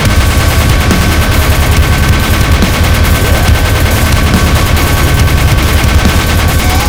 minifun_shoot.wav